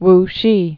(wshē)